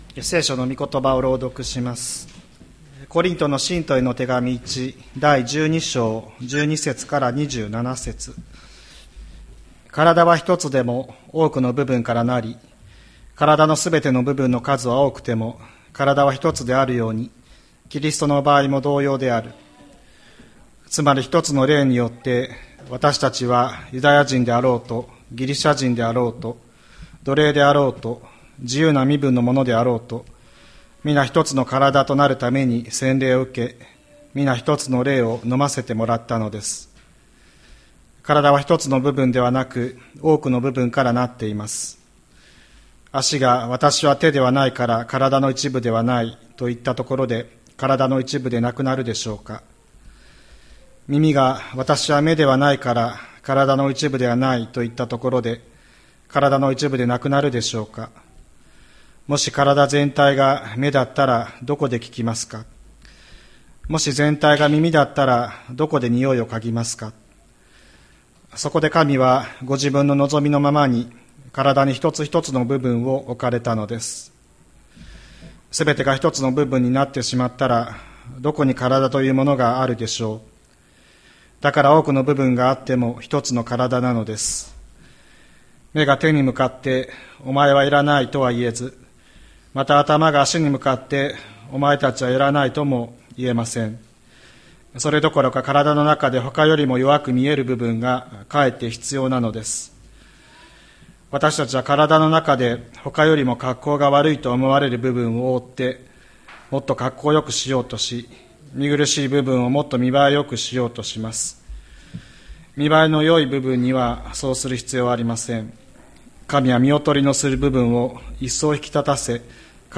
2024年06月16日朝の礼拝「聖なる者の交わり」吹田市千里山のキリスト教会
千里山教会 2024年06月16日の礼拝メッセージ。